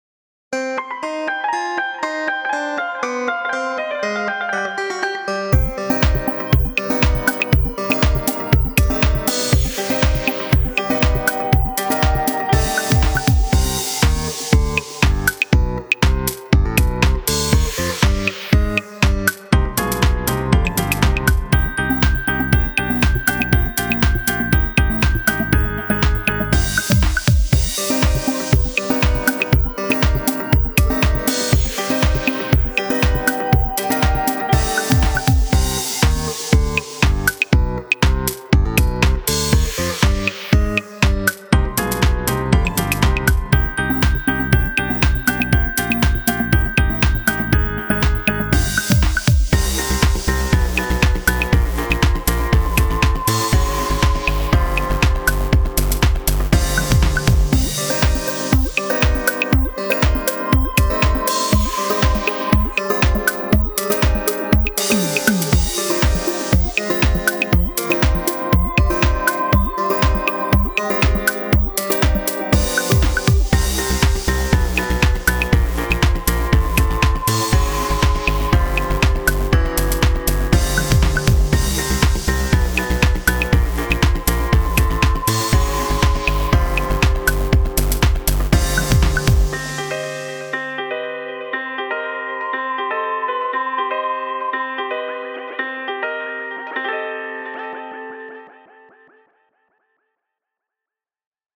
【Instrumental（BPM 120）】 mp3 DL ♪